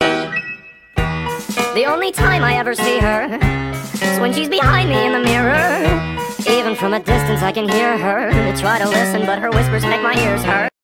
its just recorded from my phone